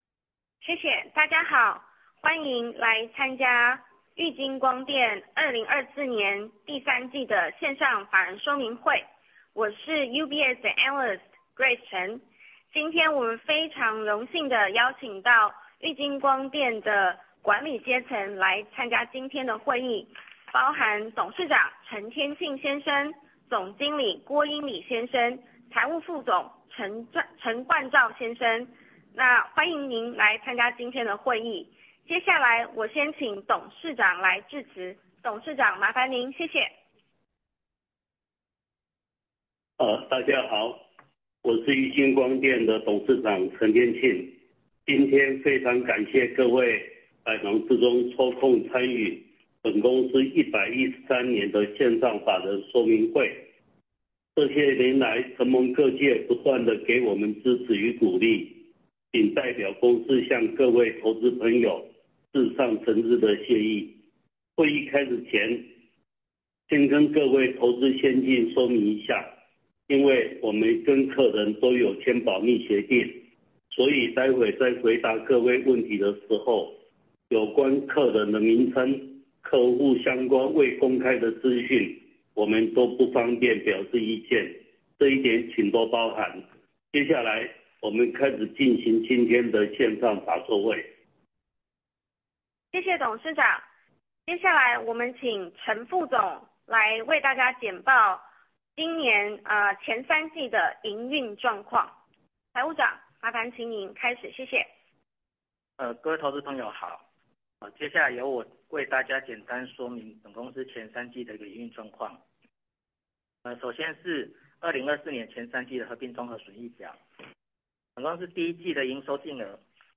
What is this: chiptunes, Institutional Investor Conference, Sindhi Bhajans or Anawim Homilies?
Institutional Investor Conference